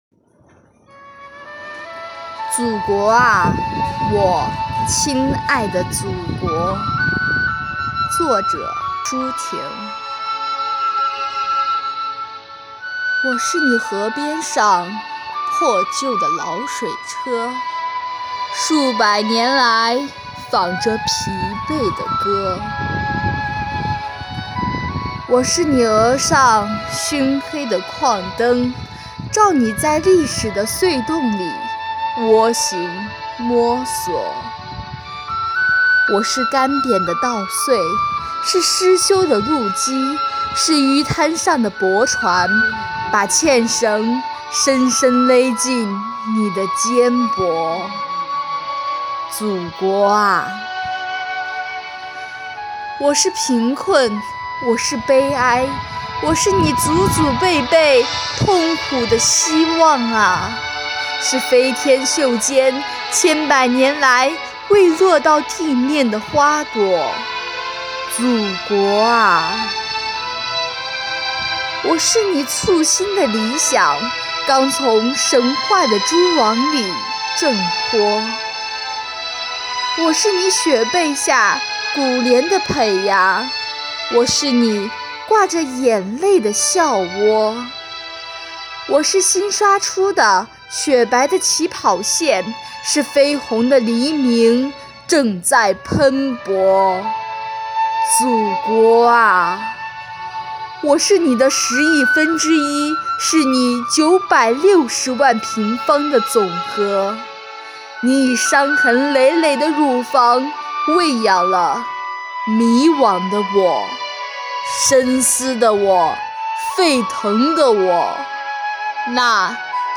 “悦读·新知·致敬”主题朗读比赛|优秀奖